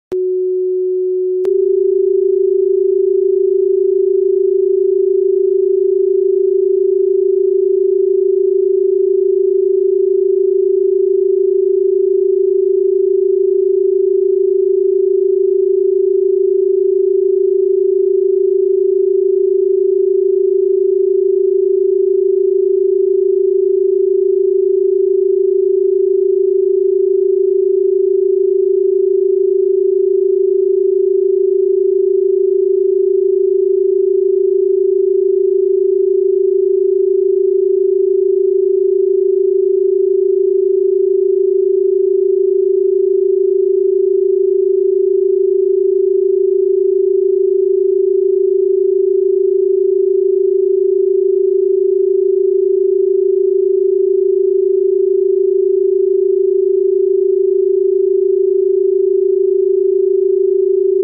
⚜ 369 + 396 Hz → THE VIBRATION OF UNSTOPPABLE REALIGNMENT & EMOTIONAL REBIRTH This sacred merge activates your root coding, clears karmic cycles, and aligns your subconscious with divine purpose.